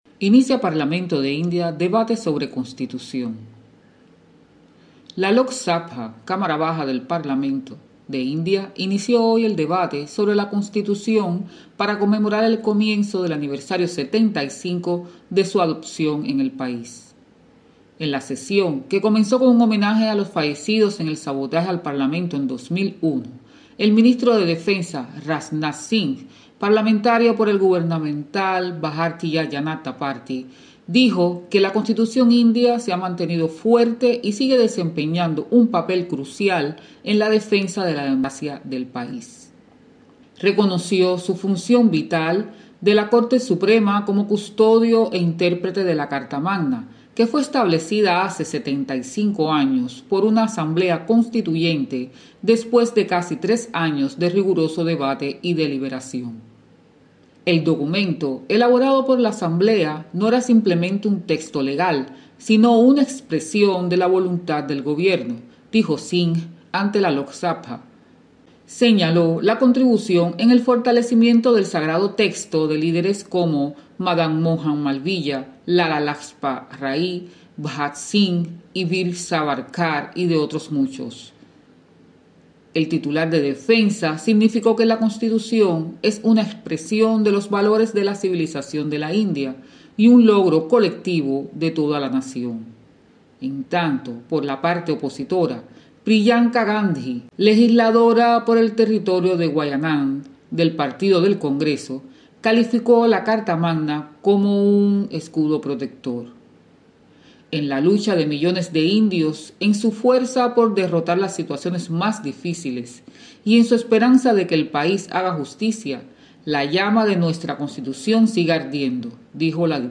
desde Nueva Delhi